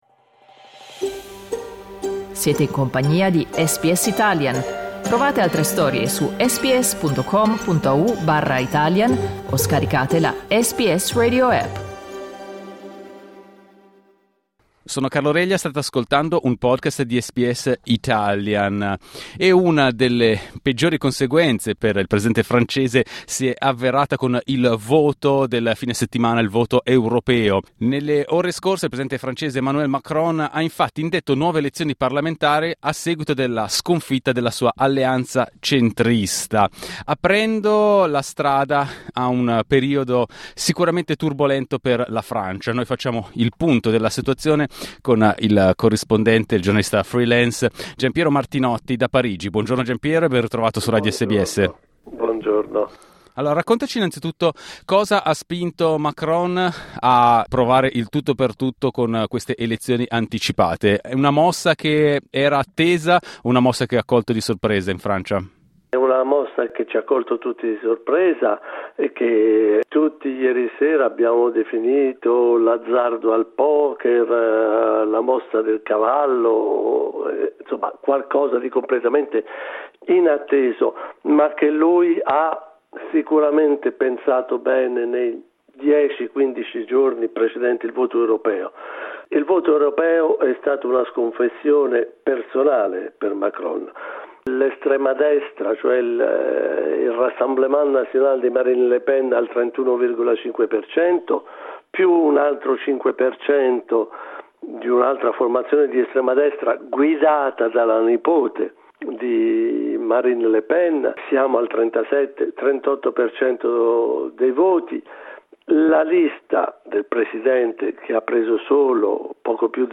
Ascolta l’analisi del giornalista da Parigi